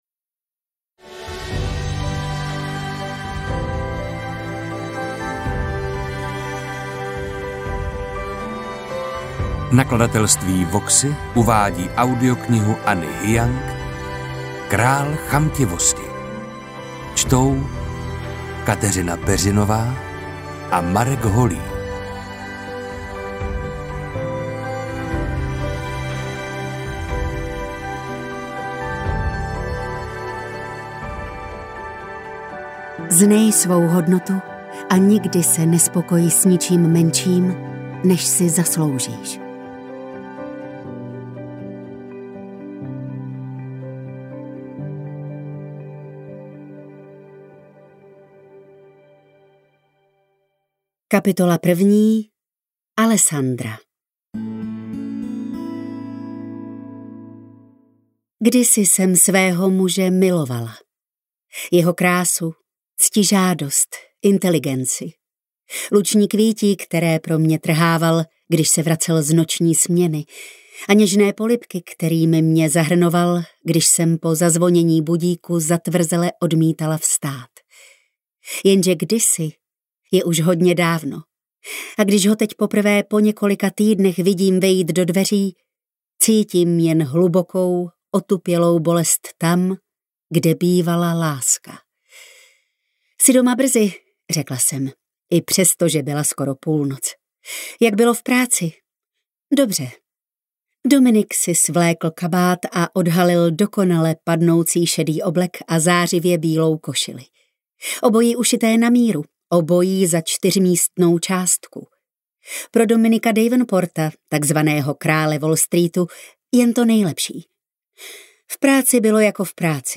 AudioKniha ke stažení, 45 x mp3, délka 9 hod. 22 min., velikost 512,4 MB, česky